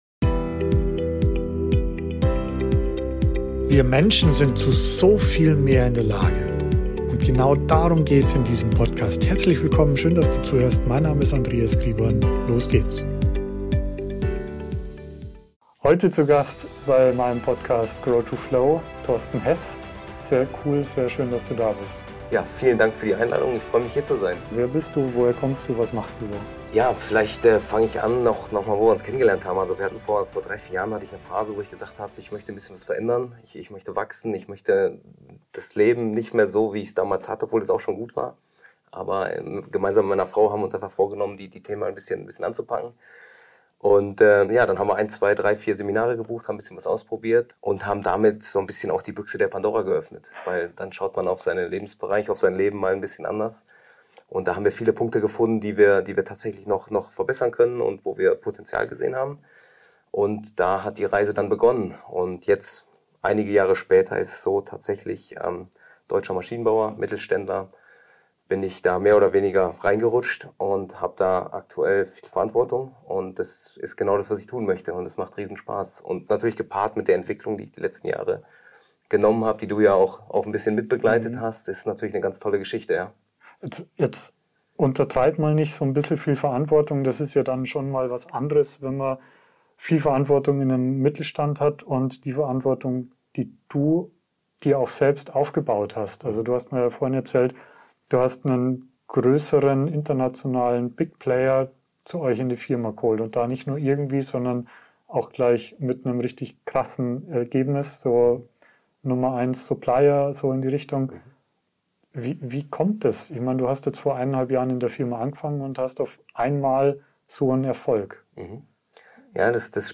Erster Teil vom Interview